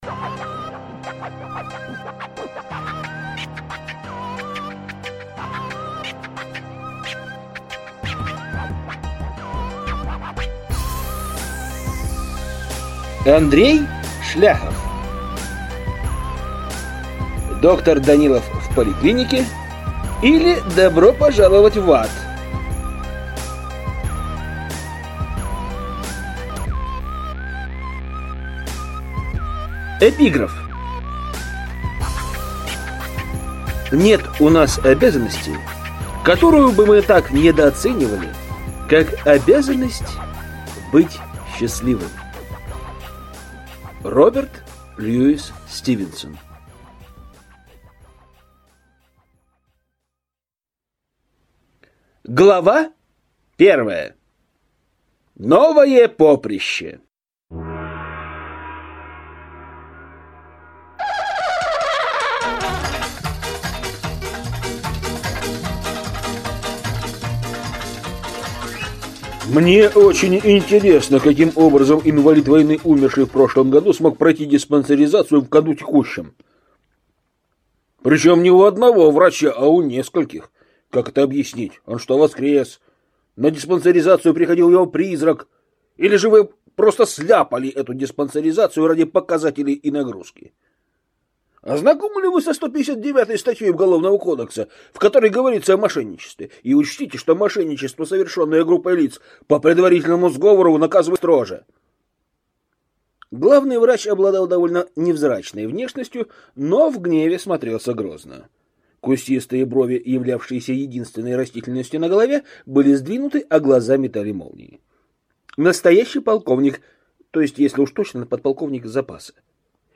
Аудиокнига Доктор Данилов в поликлинике, или Добро пожаловать в ад!